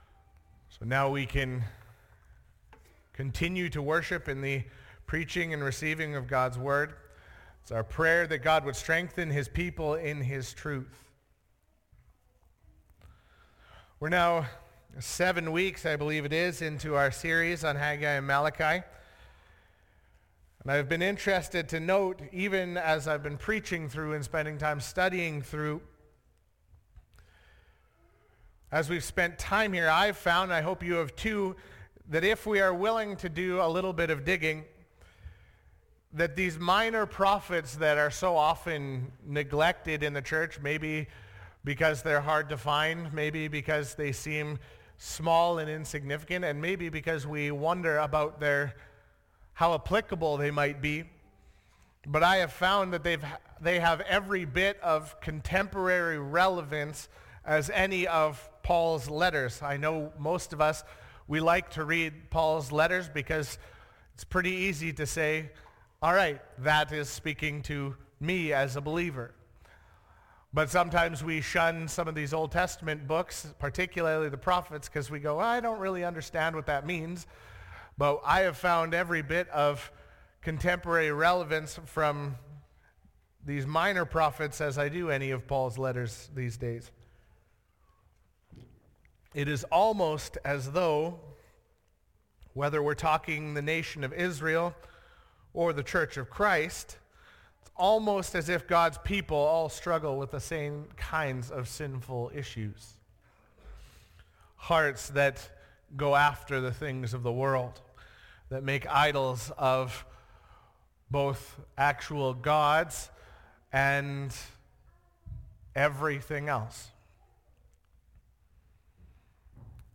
Sermons | Elk Point Baptist Church